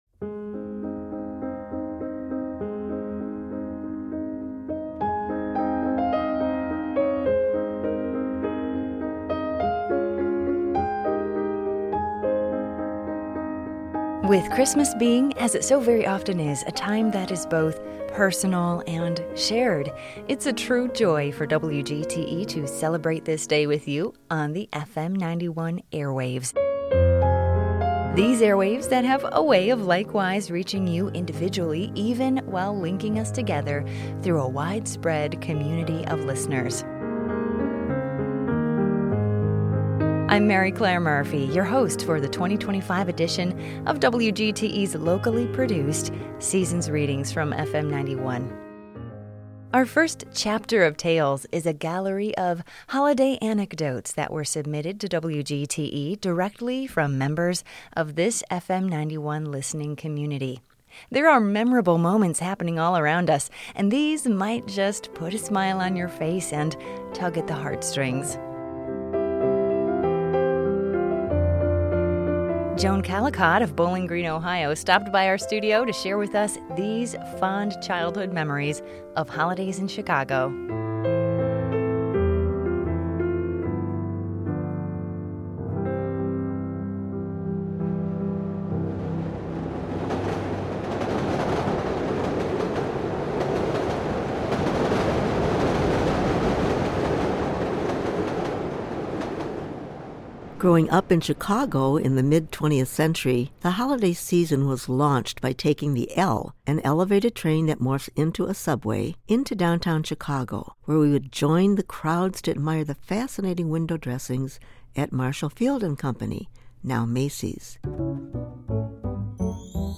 WGTE's 2025 edition of Seasons Readings from FM 91 is a well-stuffed stocking full of holiday stories from the FM 91 community and other festive tales for the family.